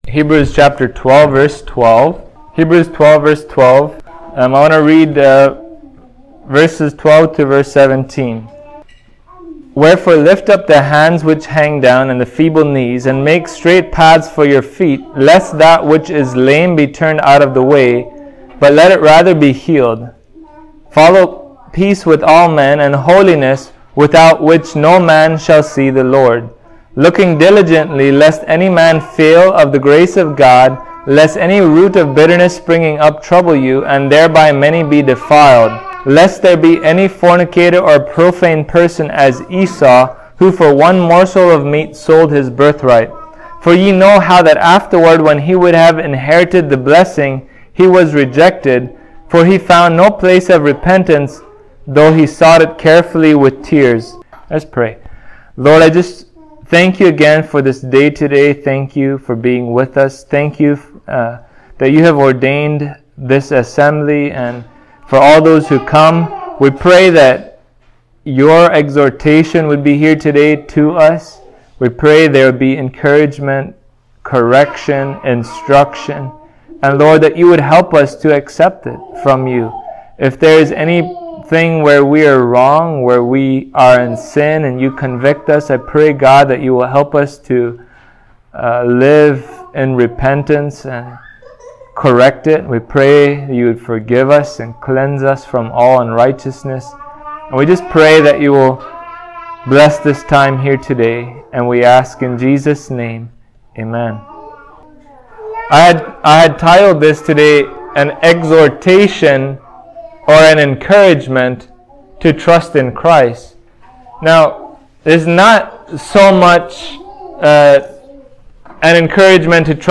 Hebrews 12:12-17 Service Type: Sunday Morning As Believers